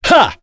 Nicx-Vox_Attack2.wav